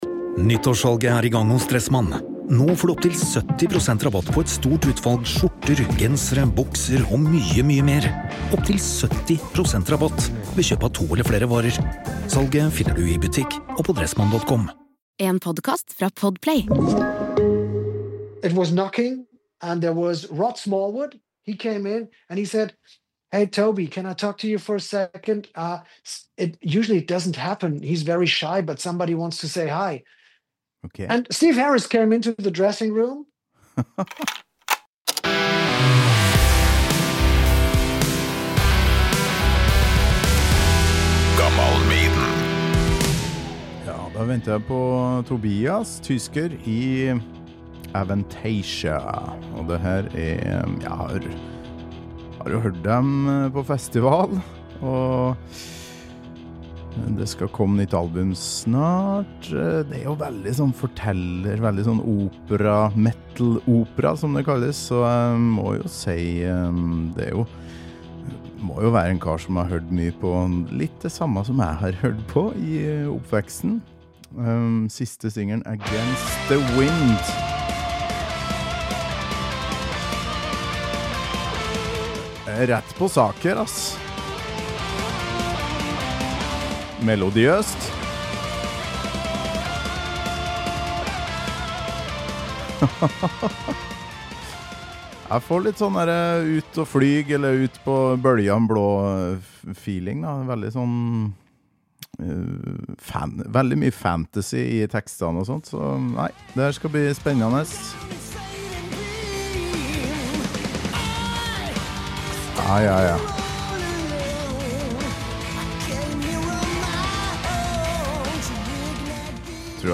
Energisk type dette, så her går det unna!